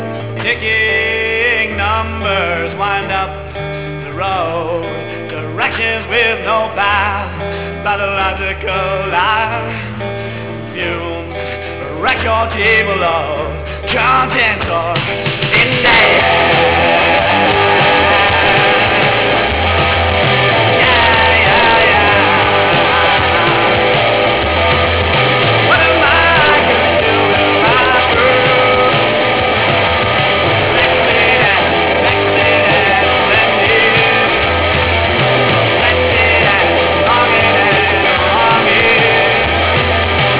vocals, guitar
bass, vocals